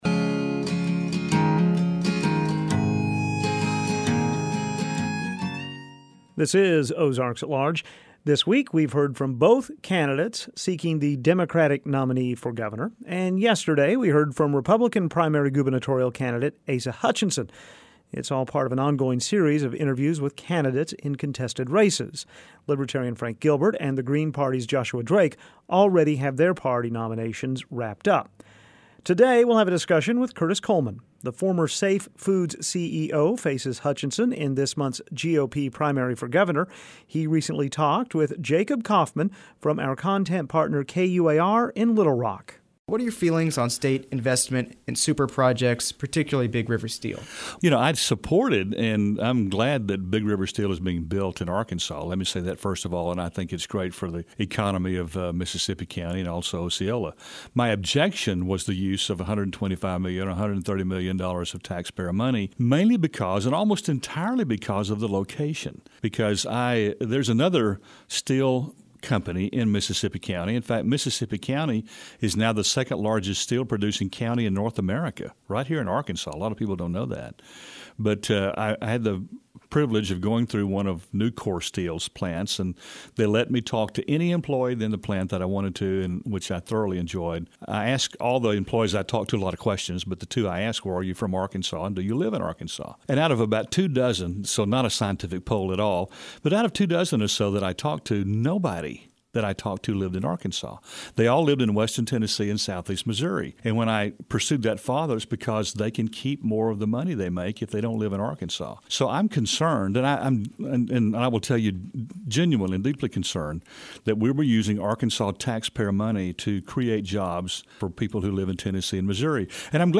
Our content partner KUAR in Little Rock is interviewing Arkansas' gubernatorial candidates.